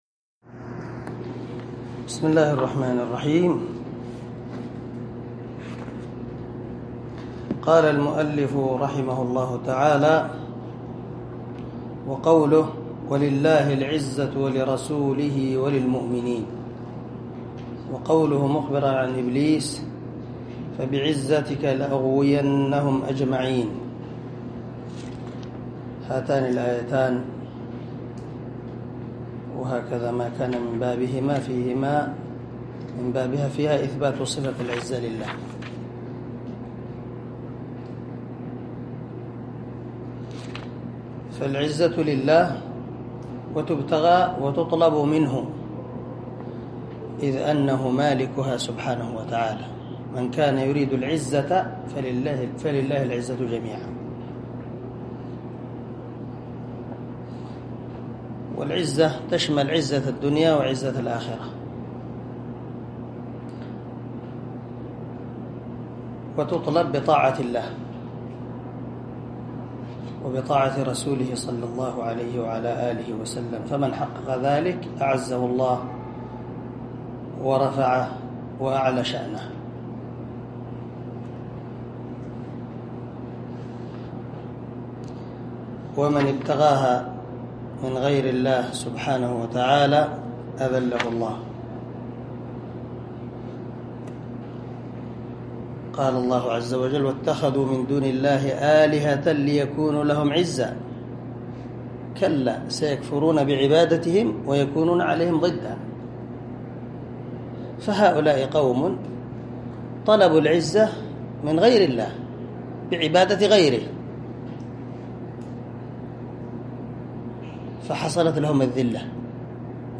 عنوان الدرس: الدرس الثاني الثلاثون
دار الحديث- المَحاوِلة- الصبيحة.